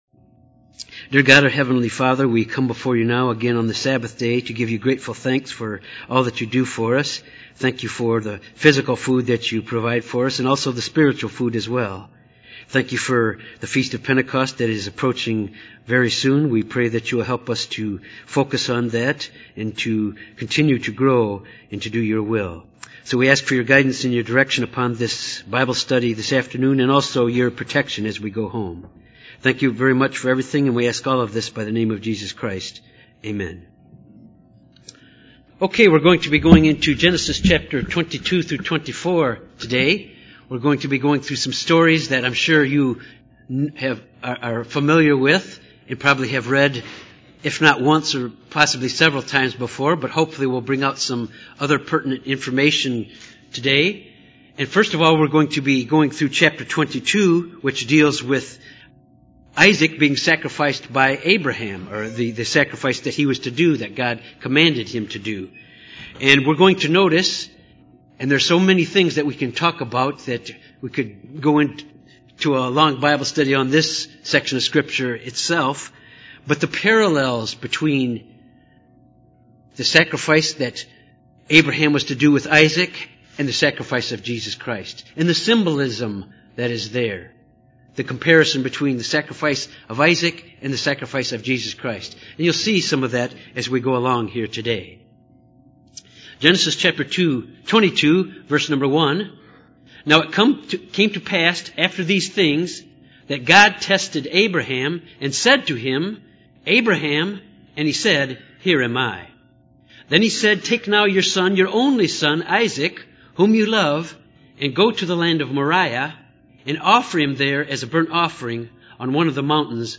This Bible study focuses on Genesis 22-24. Abraham was commanded by God to offer Isaac as a sacrifice.
Given in Little Rock, AR